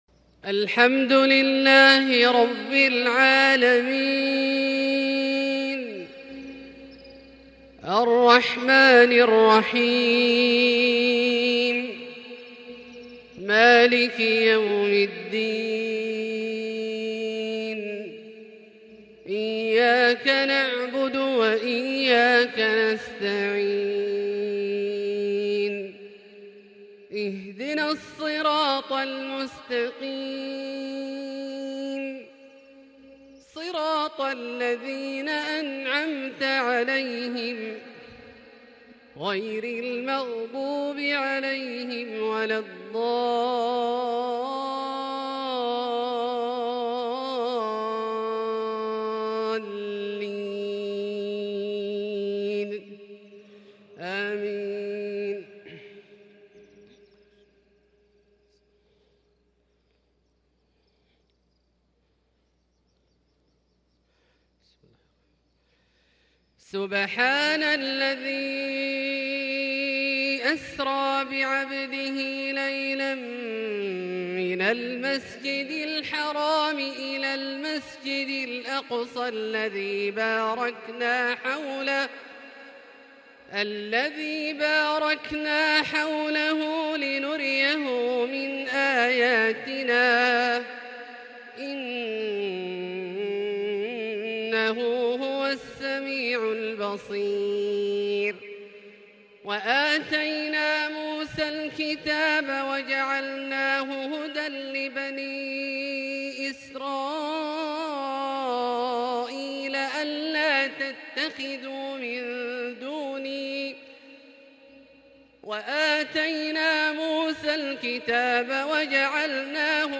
فجرية بياتية تاريخية من سورة الإسراء (1-17) الأربعاء 7 شوال 1442هـ > ١٤٤٢ هـ > الفروض - تلاوات عبدالله الجهني